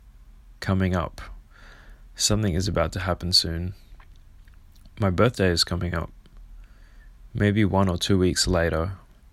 句動詞 come up のもつ様々な意味のうちの一つで、何かの出来事がもうすぐ起きようとすることを言いますが、通常進行形で用いられます。 英語ネイティブによる発音は下記のリンクをクリックしてください。